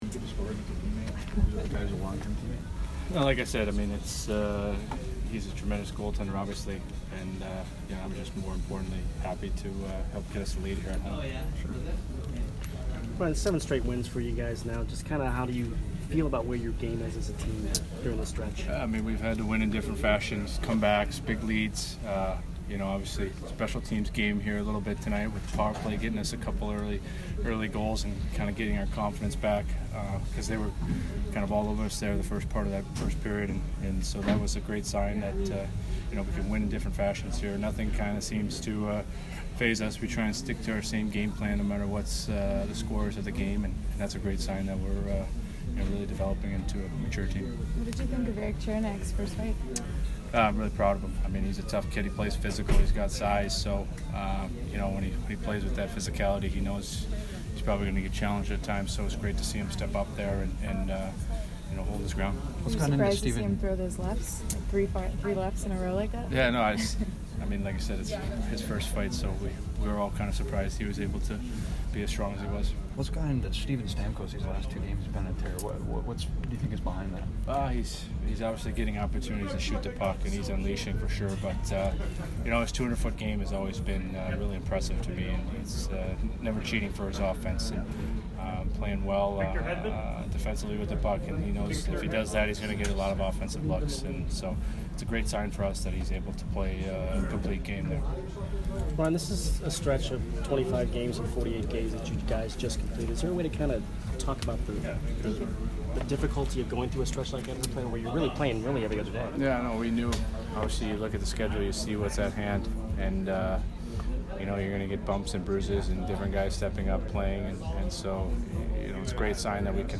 Ryan McDonagh post-game 12/10